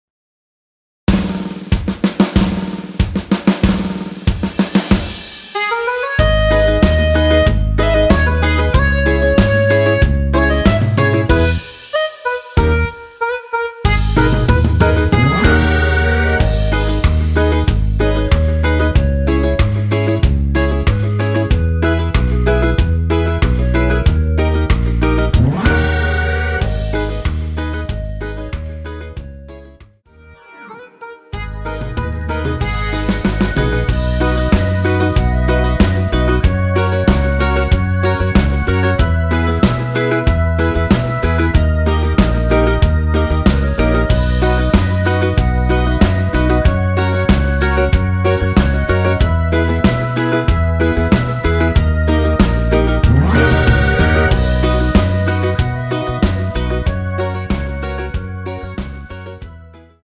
원키에서(+2)올린 멜로디 포함된 MR입니다.
G#m
앞부분30초, 뒷부분30초씩 편집해서 올려 드리고 있습니다.
(멜로디 MR)은 가이드 멜로디가 포함된 MR 입니다.